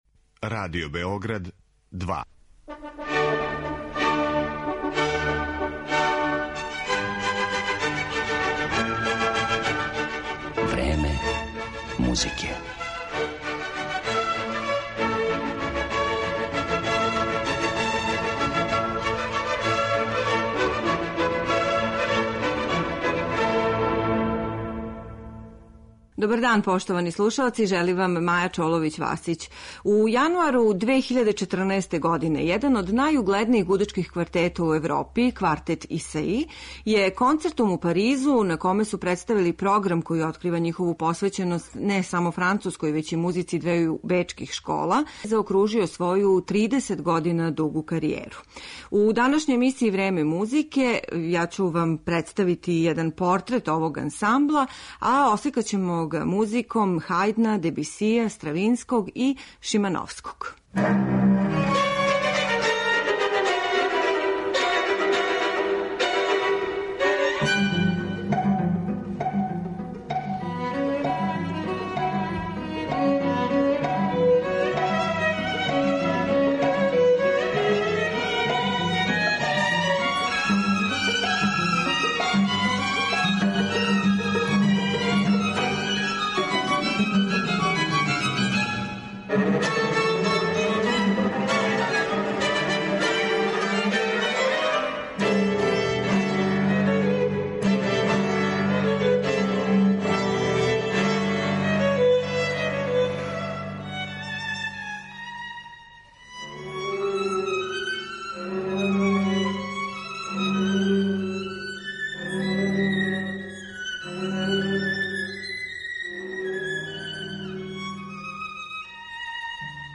Гудачки квартет „Исаи”
Али, током три деценије постојања, изузетан музички укус и рафинираност свирања, као и сензуалност и техничко мајсторство сваког члана понаособ, обезбедили су овом квартету место међу најеминентнијим и „најаристократскијим" камерним саставима XX века. У данашњој емисији ћемо га представити музиком Хајдна, Стравинског, Дебисија и Шимановског.